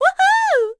Juno-Vox_Happy4.wav